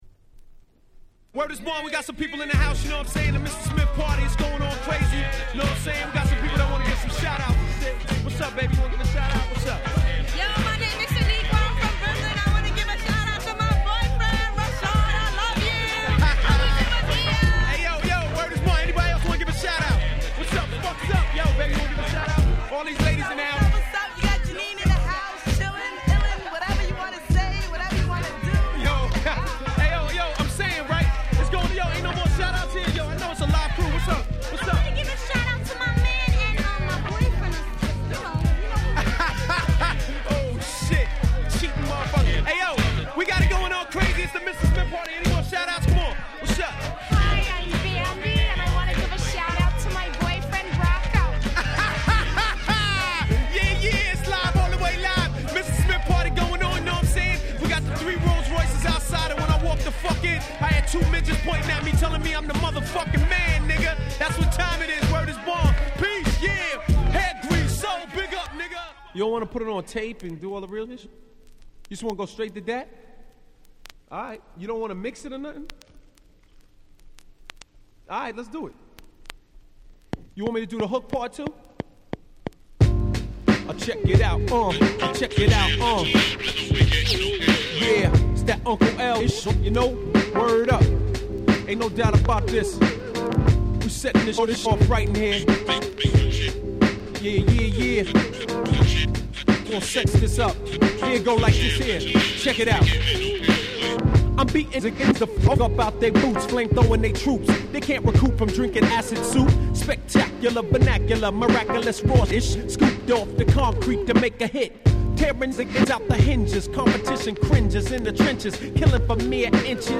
95' Super Hit Hip Hop LP !!